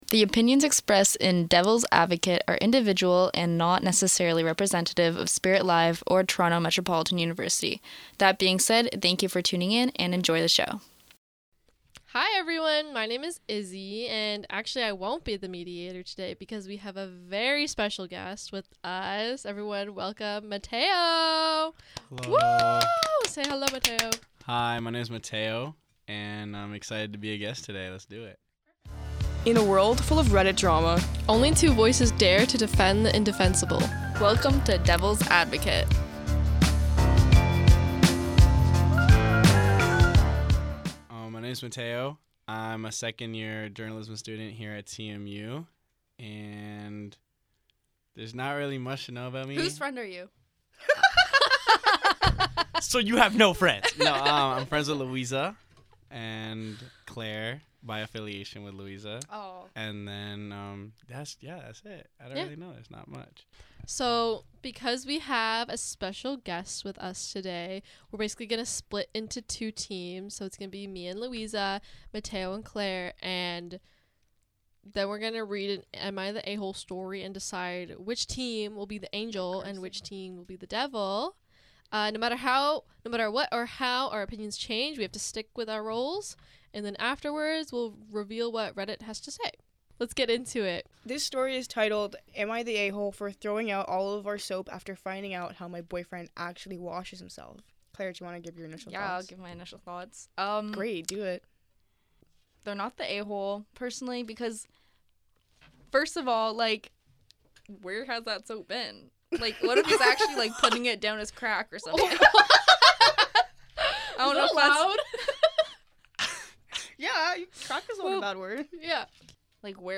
These debates will be between two hosts, one against and one for, while the third host reads, moderates and acts as a commentator of the discussion.